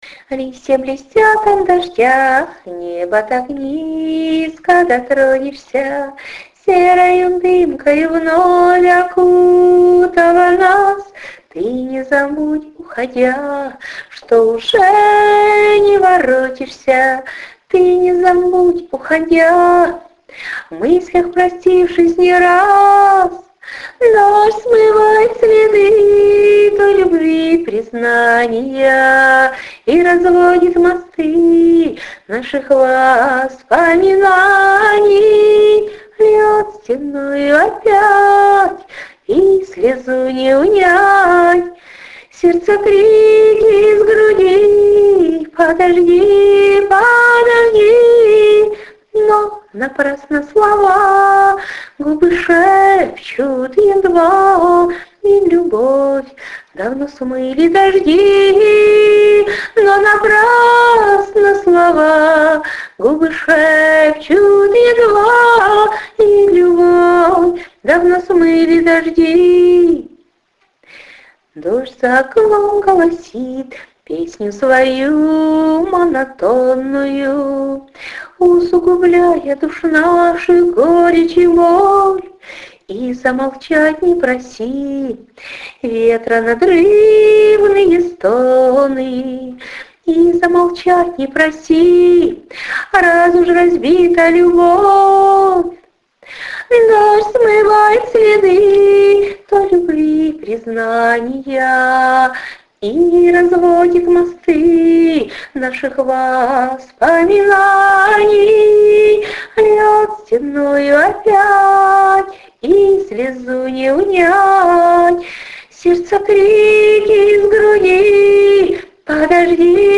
А качество исполнения ужасное...Сто лет не слушала... cry
Красивая песня!.. 12 39 39 но и тут снова скажу, что Вам нужно хорошо подумать о музыкальном сопровождении 39 39 give_rose